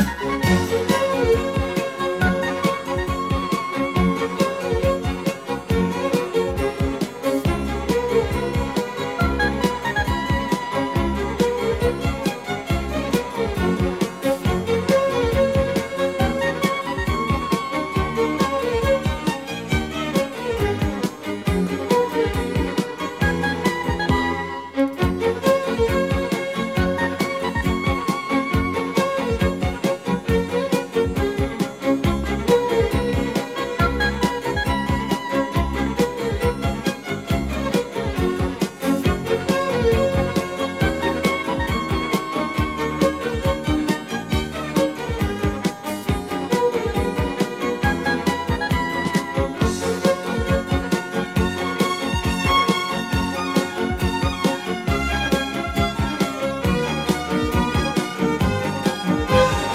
Genre:Neo Classical